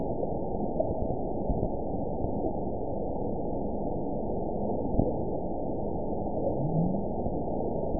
event 922646 date 02/23/25 time 21:42:50 GMT (9 months, 1 week ago) score 9.47 location TSS-AB01 detected by nrw target species NRW annotations +NRW Spectrogram: Frequency (kHz) vs. Time (s) audio not available .wav